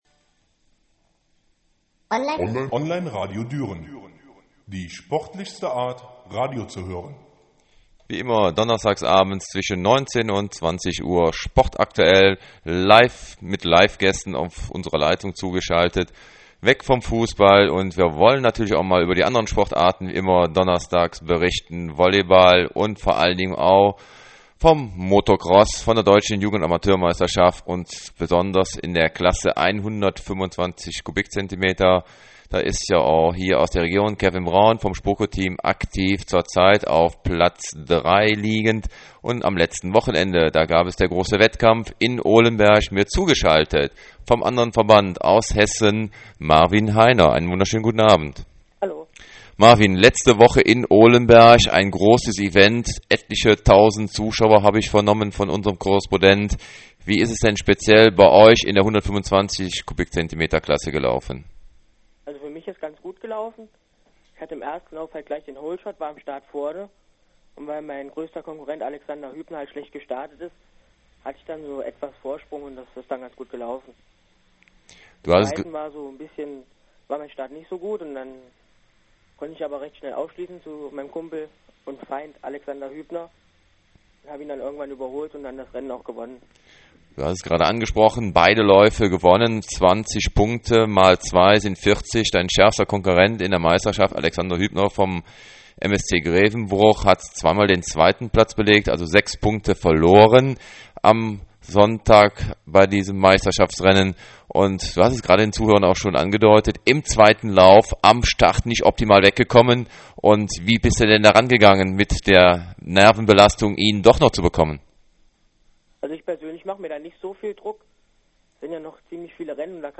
Online-Interview